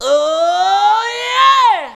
OOOOOYEAH.wav